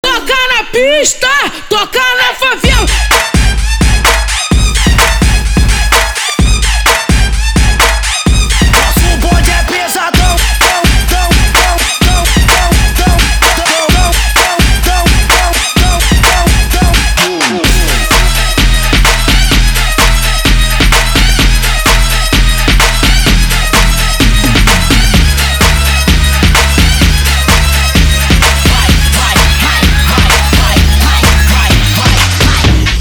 • Качество: 320, Stereo
Trap